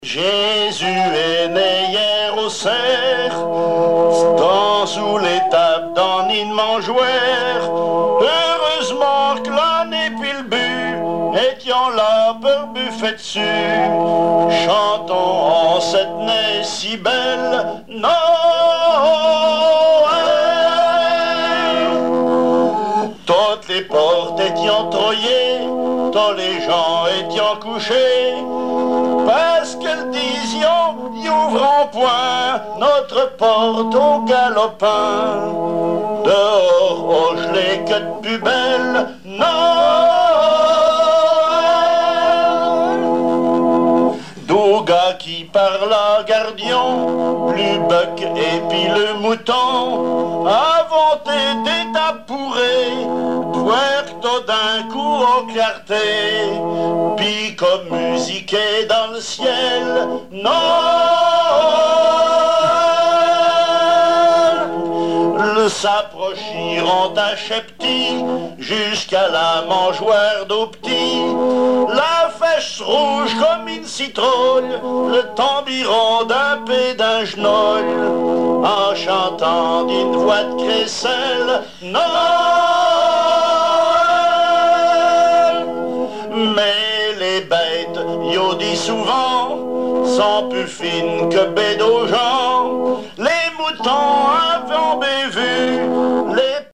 émission La fin de la Rabinaïe sur Alouette
Catégorie Pièce musicale inédite